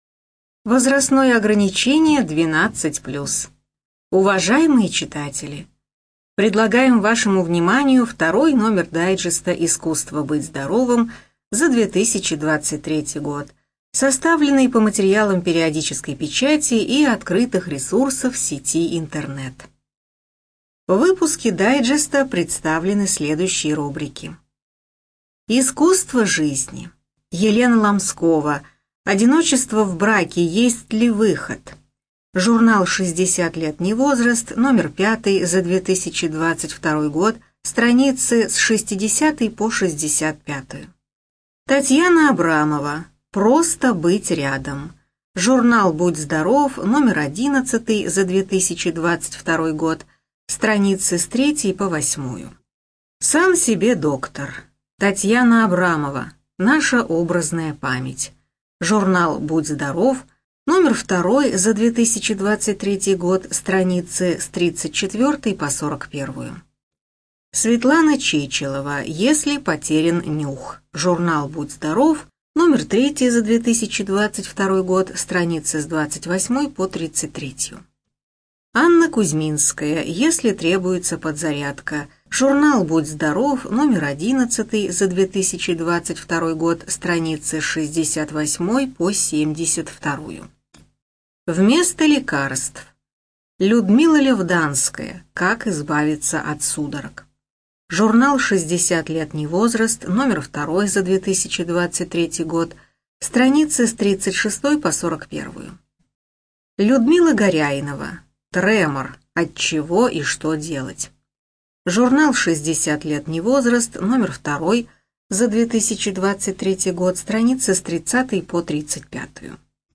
Студия звукозаписиКемеровская областная специальная библиотека для незрячих и слабовидящих